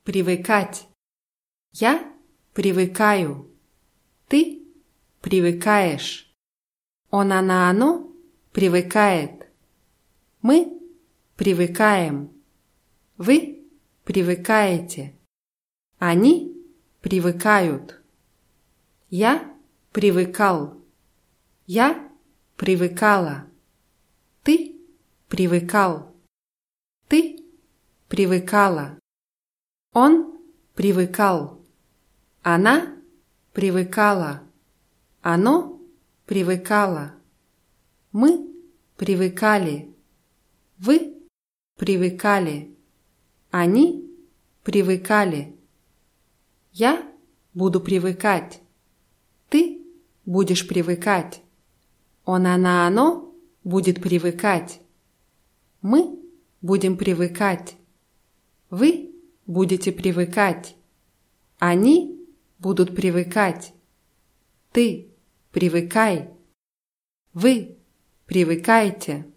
привыкать [prʲiwykátʲ]